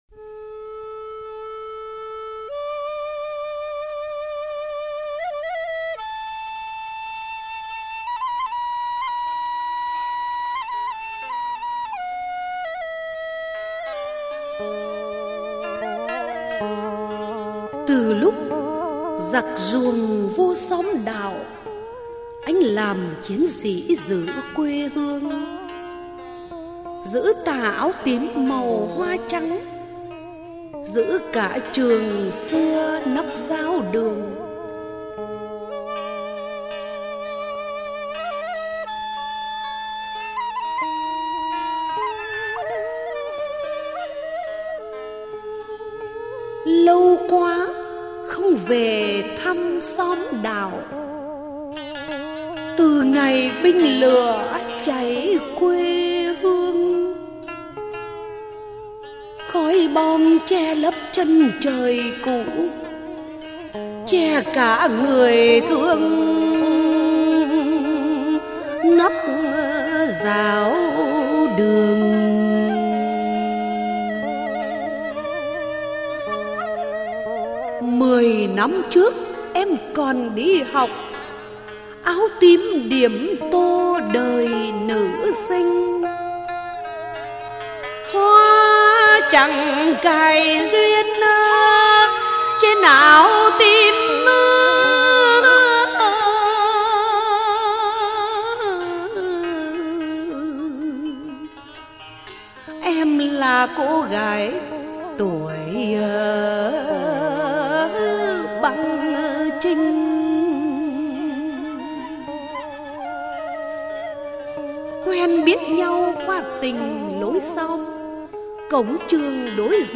Ngâm Thơ | Sáng Tạo